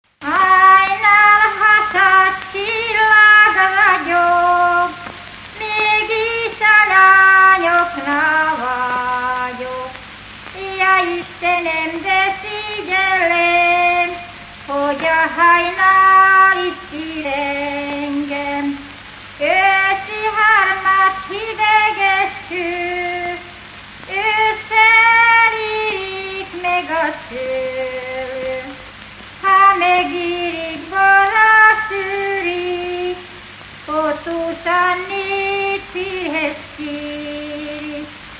Felföld - Nyitra vm. - Menyhe
Stílus: 3. Pszalmodizáló stílusú dallamok
Szótagszám: 8.8.8.8
Kadencia: b3 (VII) b3 1